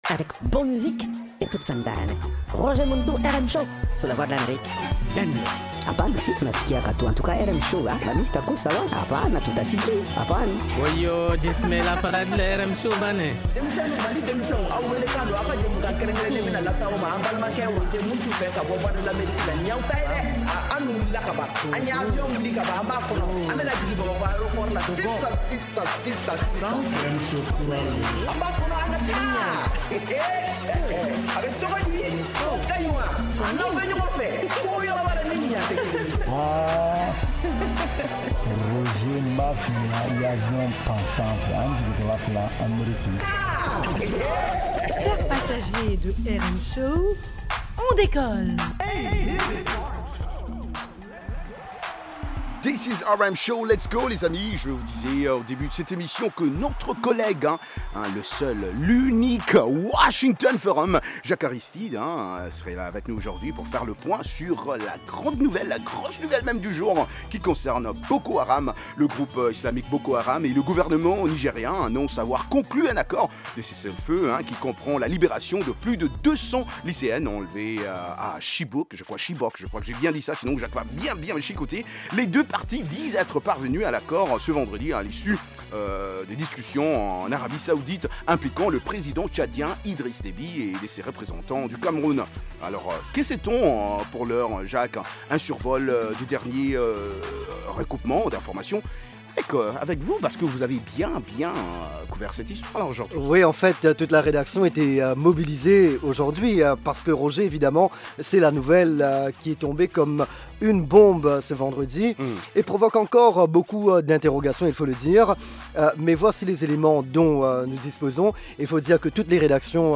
une sélection spéciale de musique malienne et internationale. Participez aussi dans Micro-Mali pour debattre des sujets socio-culturels.